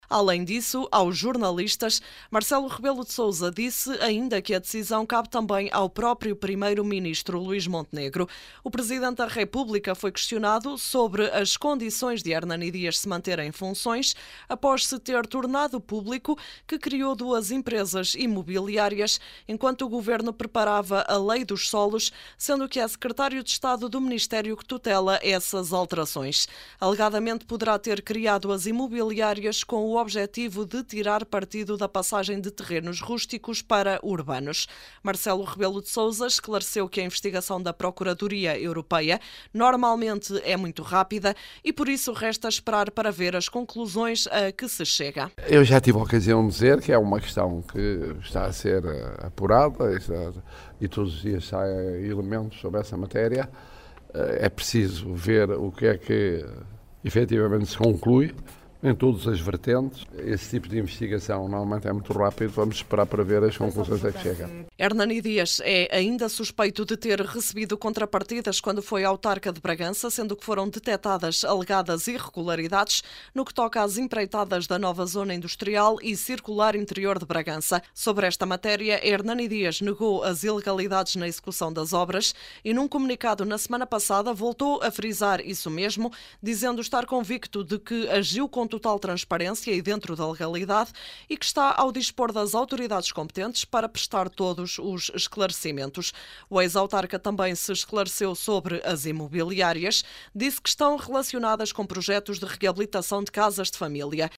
Além disso, aos jornalistas, Marcelo Rebelo de Sousa disse ainda que a decisão cabe também ao próprio primeiro-ministro, Luís Montenegro.